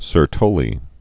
(sər-tōlē, sĕr-)